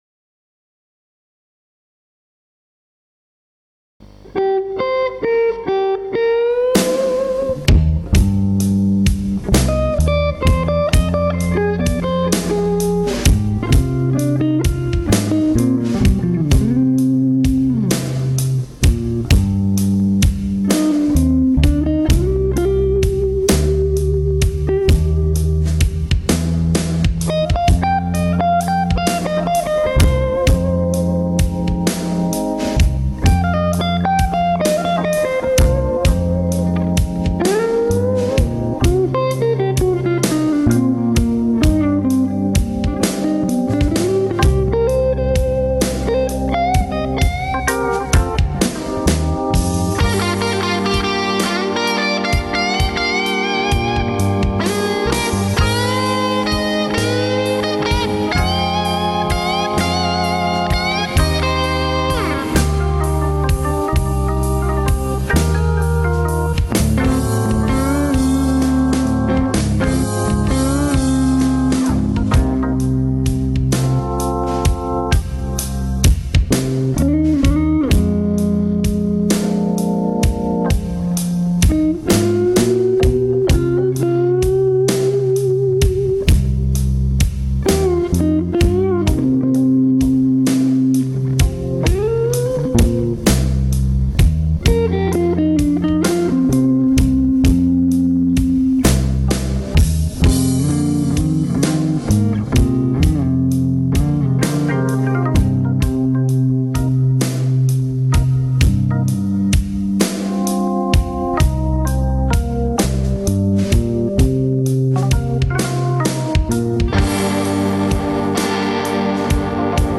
Gibson Les Paul Studio